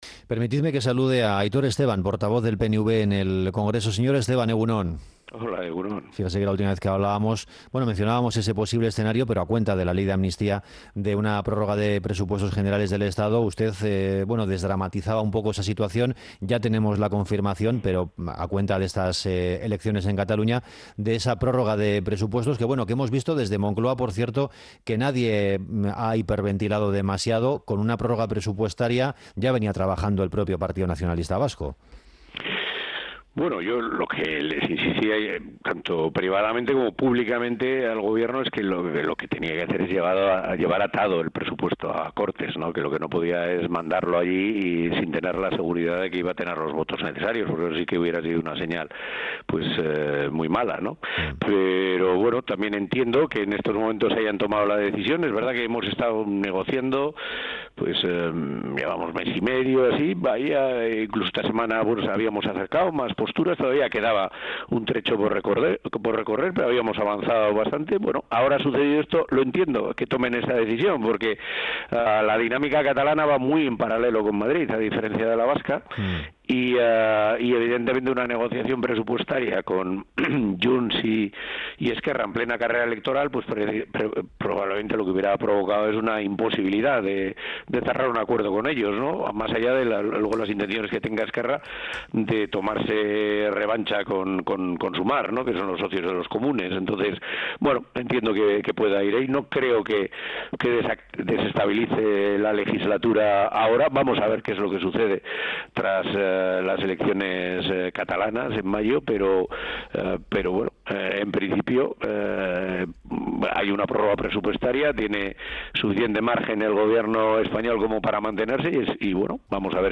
El portavoz del PNV en el Congreso, Aitor Esteban, en una entrevista en Onda Vasca .